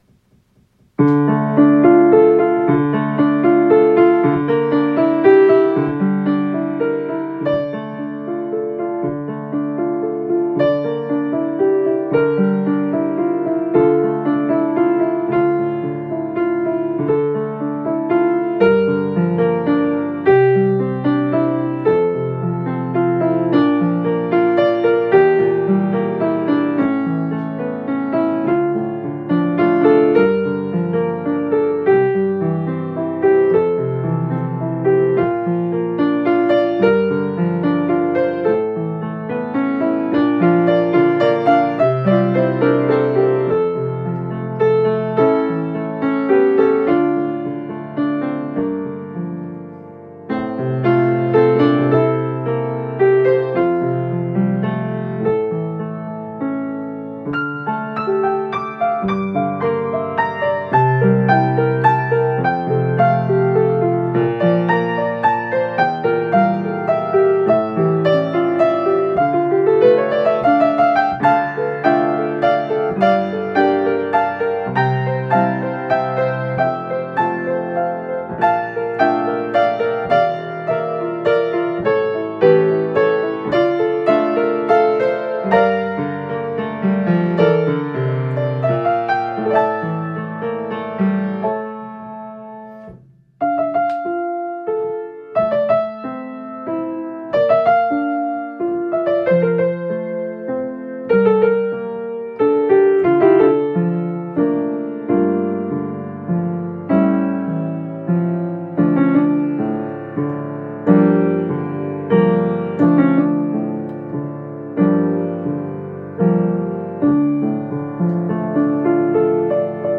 For: Piano Solo